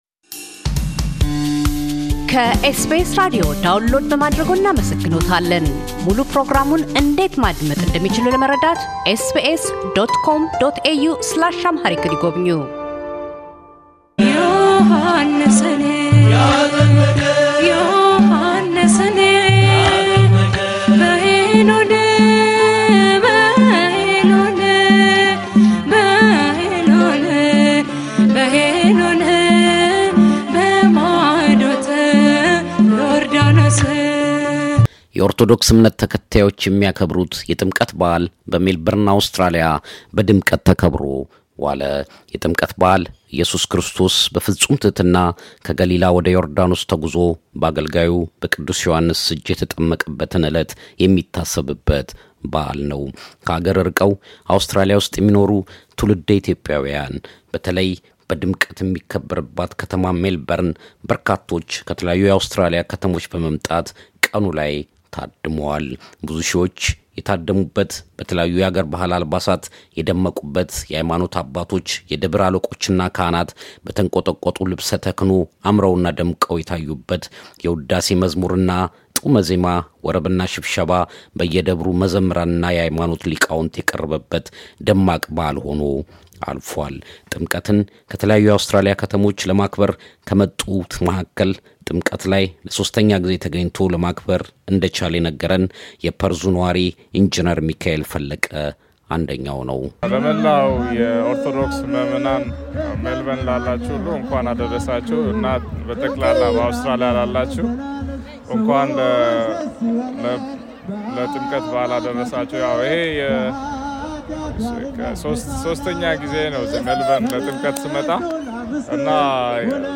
በሜልበር ፉትስክሬ መናፈሻ ስፍራ በተካሄደው የጥምቀት በአል ላይ የከተማዋ ነዋሪዎች እና ከተለያዩ የአውስትራሊያ ከተሞች የመጡ ታዳሚዎች ተገኝተዋል።